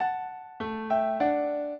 minuet3-4.wav